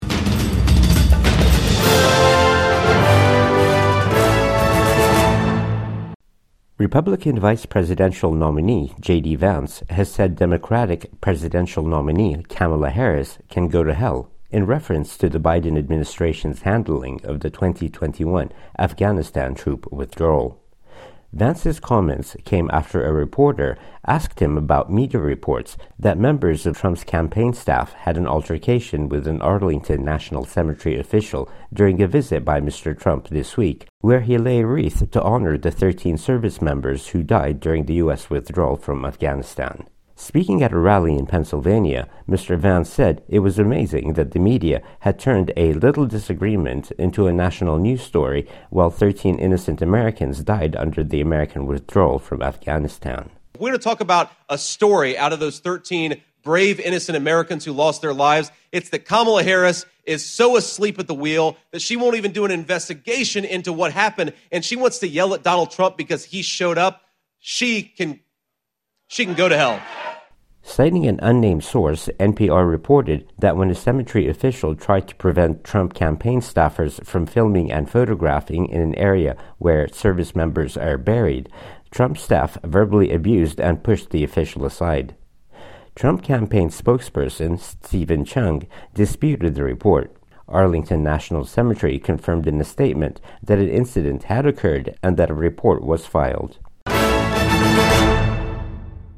US Republican vice-president nominee throws harsh words at Kamala Harris in campaign speech